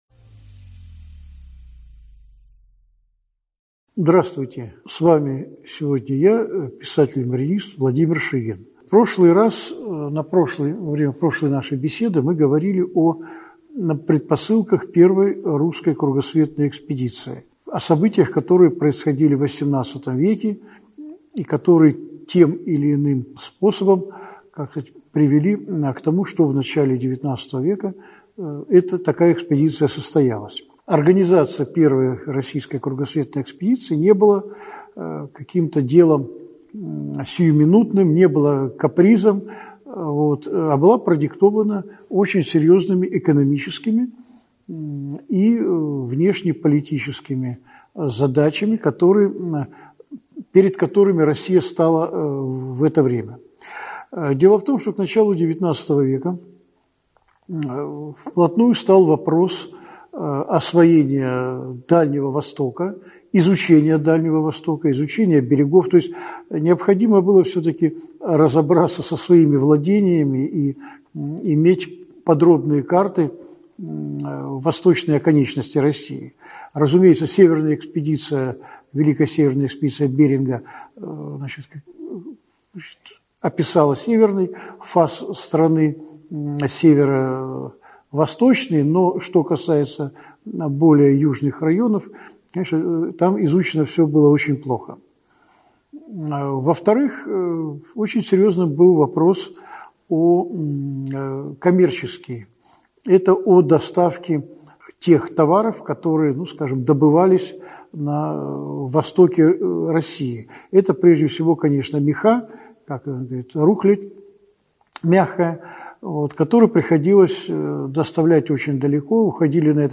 Аудиокнига Первая кругосветная экспедиция русского флота. Часть 2 | Библиотека аудиокниг